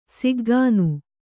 ♪ siggānu